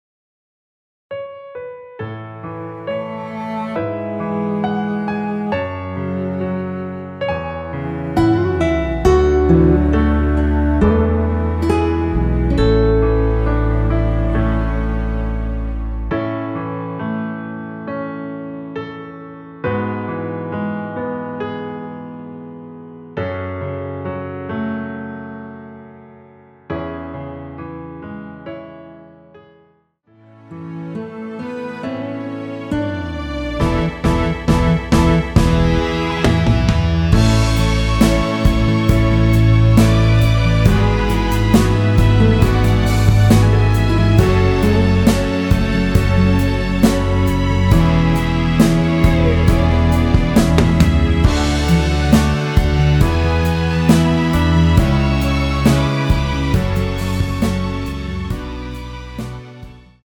앨범 | O.S.T
앞부분30초, 뒷부분30초씩 편집해서 올려 드리고 있습니다.
중간에 음이 끈어지고 다시 나오는 이유는